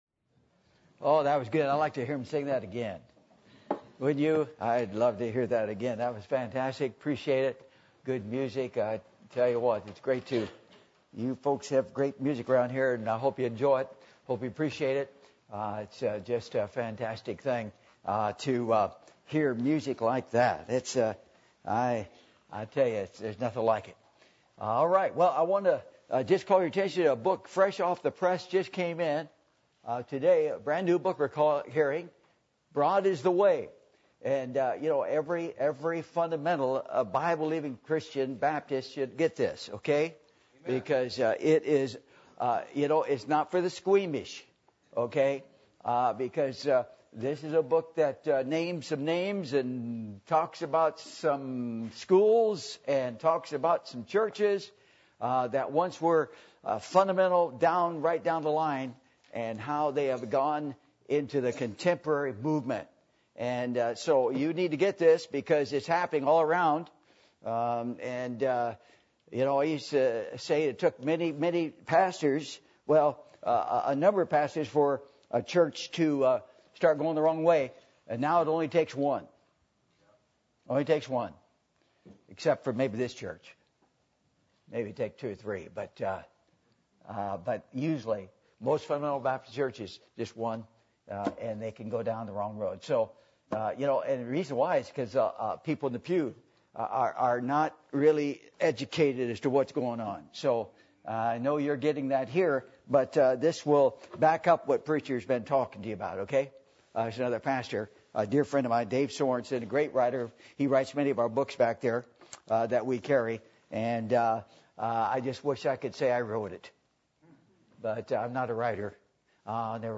Passage: John 3:1-7 Service Type: Revival Meetings %todo_render% « Are You In The Place Of Fullness Of Joy?